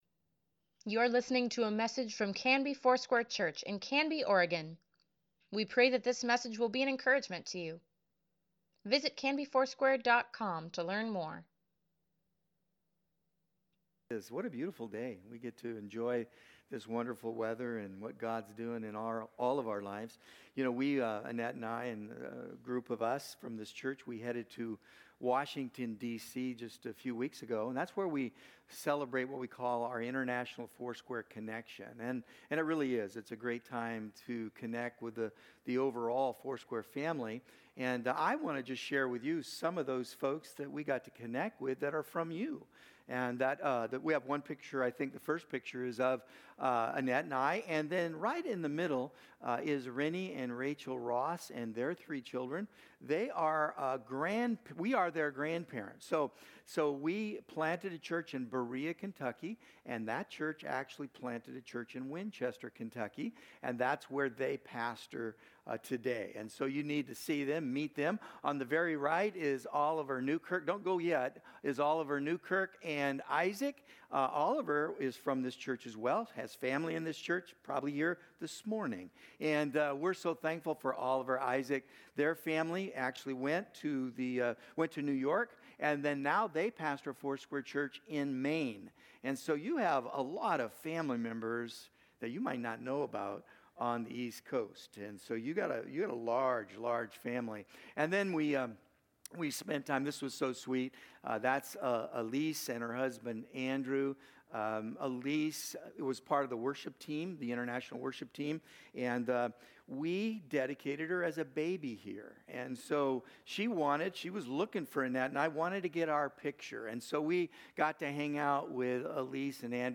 Sunday Sermon | June 9, 2024